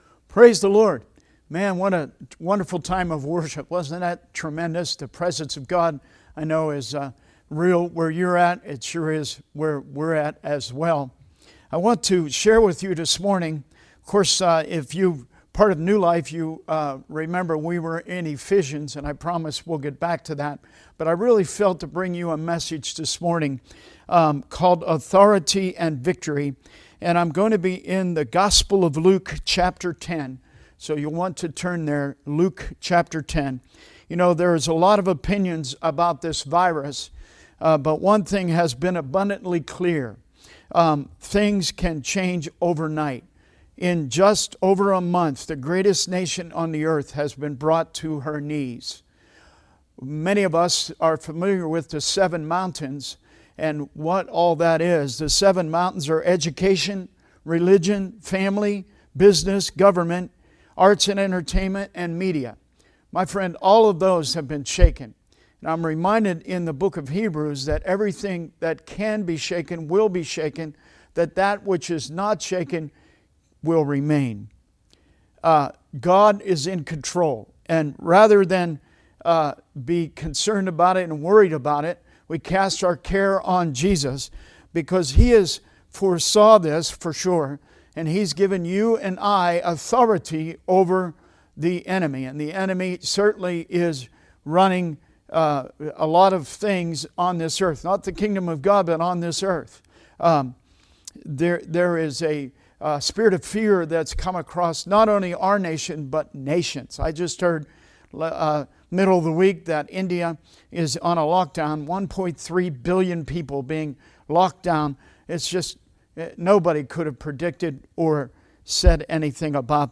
Service Type: Sunday Teaching